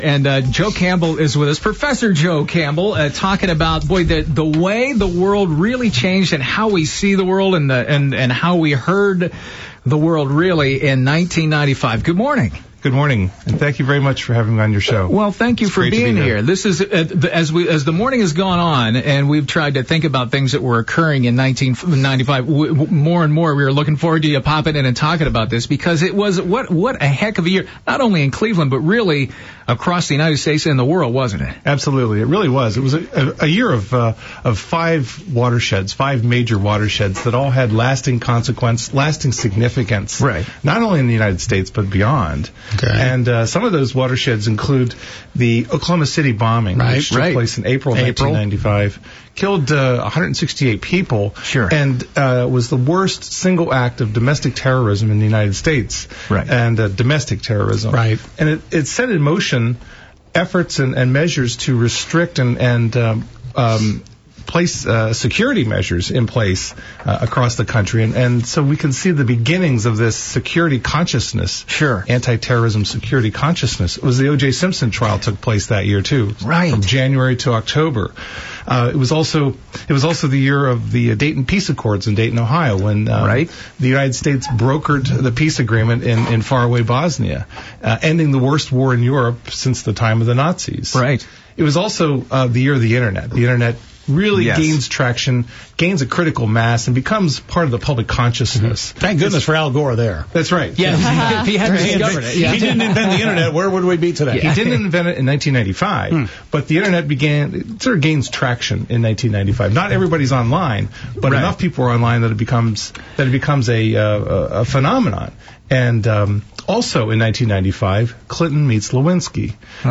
interview.mp3